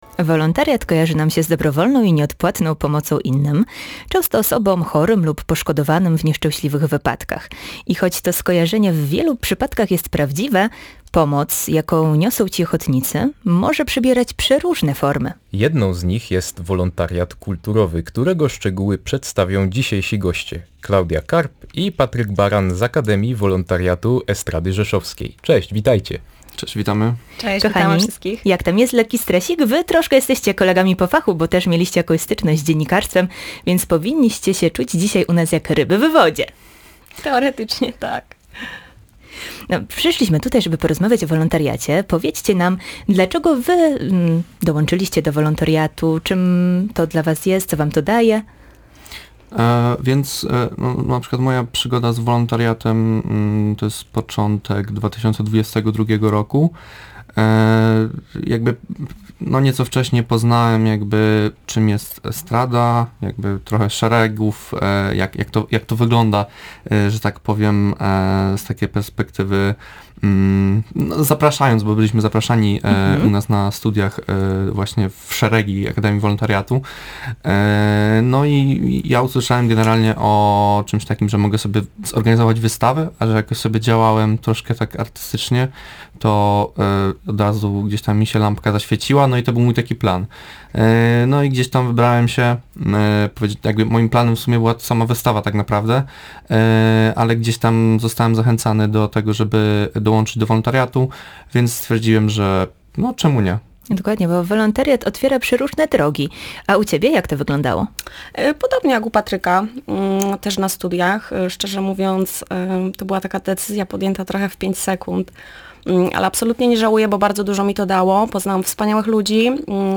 Rozmowa-GR-Akademia-Wolontariatu.mp3